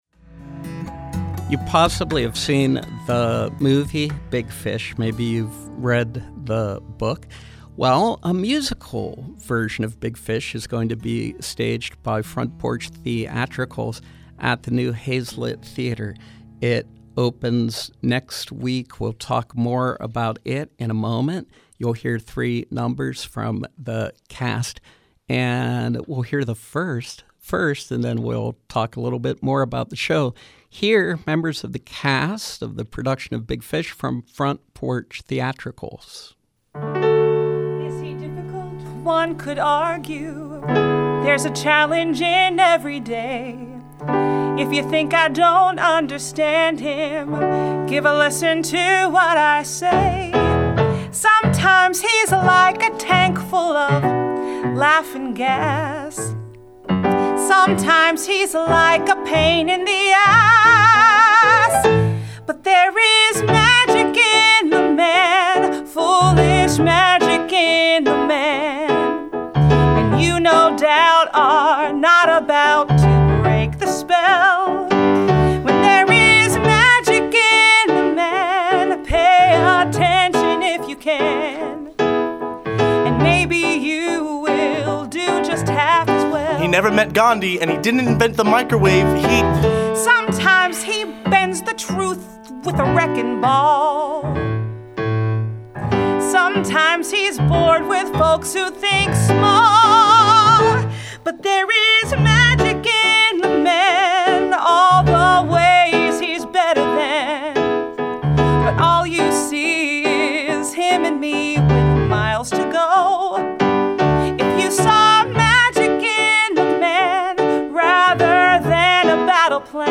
In-Studio Pop up: Front Porch Theatricals